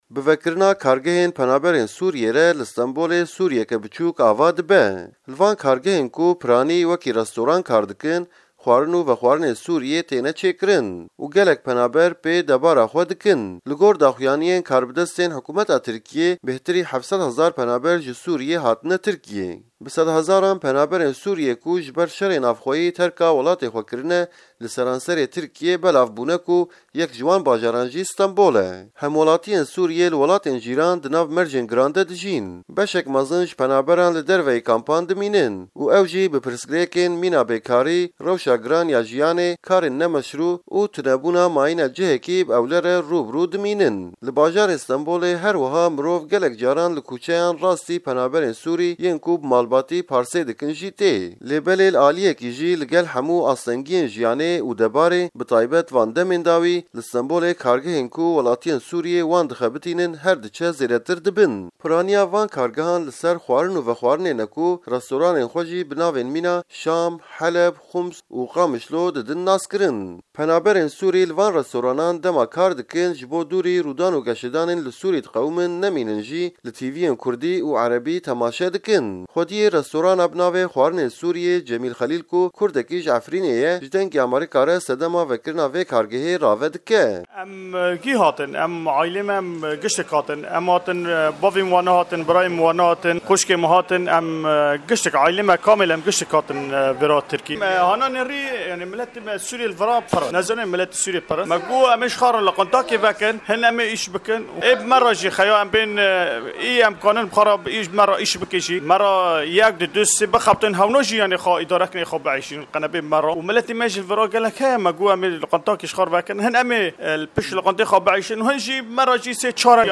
Raporta Stenbolê